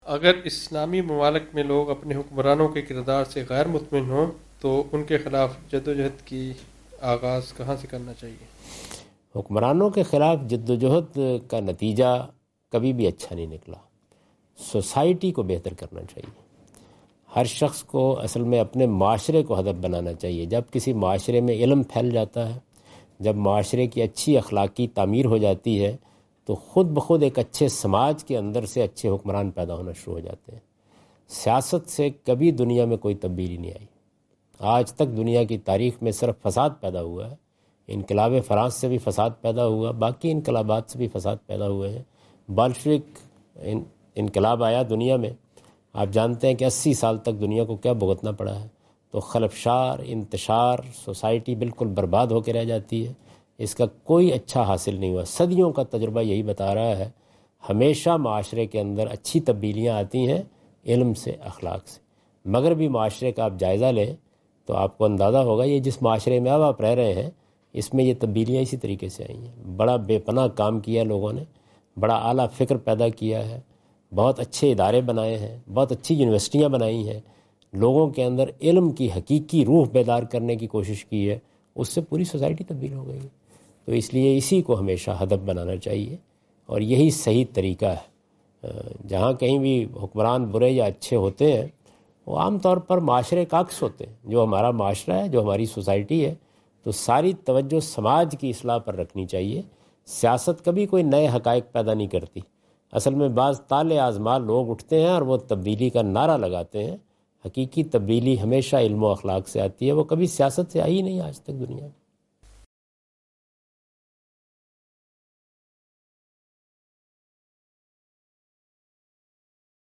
Javed Ahmad Ghamidi answer the question about "struggle against incapable governments" during his Australia visit on 11th October 2015.
جاوید احمد غامدی اپنے دورہ آسٹریلیا کے دوران ایڈیلیڈ میں "نا اہل حکومتوں کے خلاف جد و جہد" سے متعلق ایک سوال کا جواب دے رہے ہیں۔